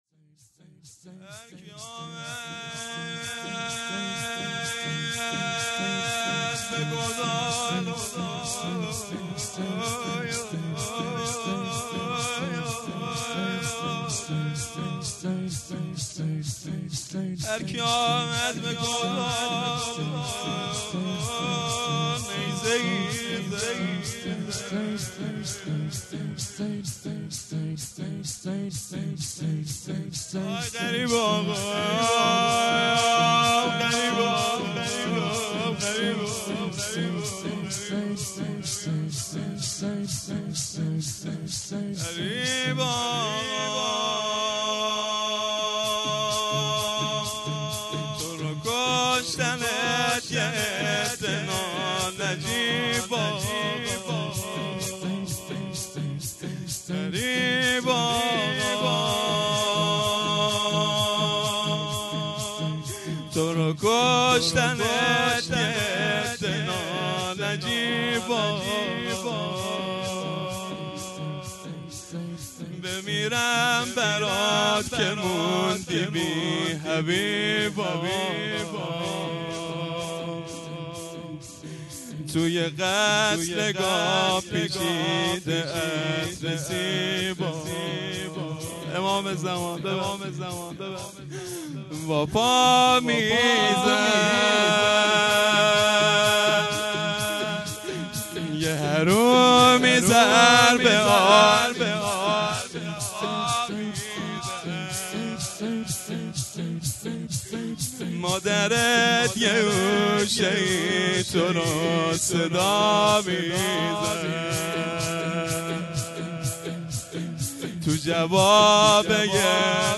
شور پایانی
مراسم پیشواز محرم الحرام 1443